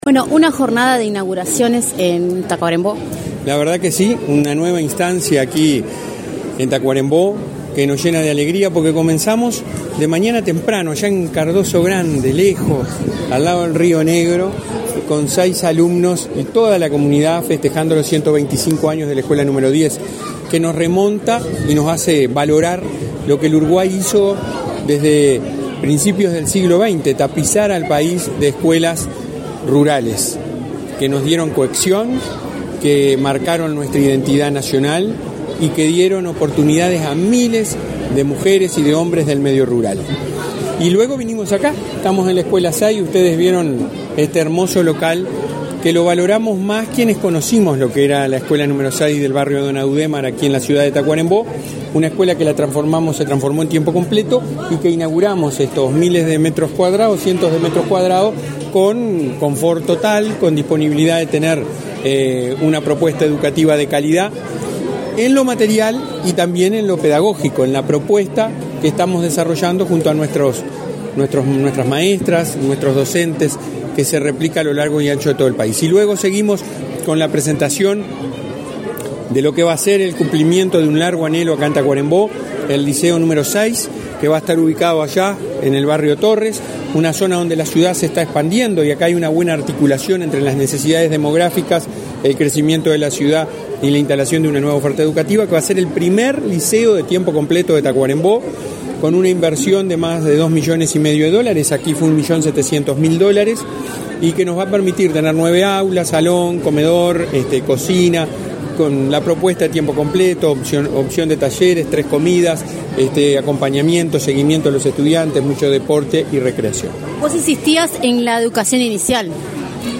Entrevista al presidente de la ANEP, Robert Silva